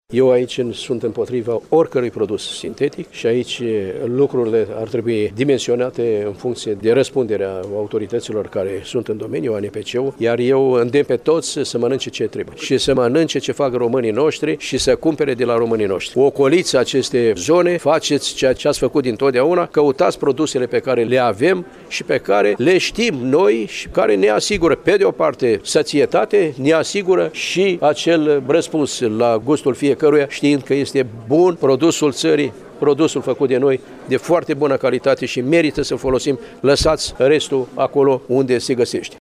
Iaşi – Ministrul Agriculturii, Petre Daea, s-a întîlnit cu fermieri şi agricultori din judeţ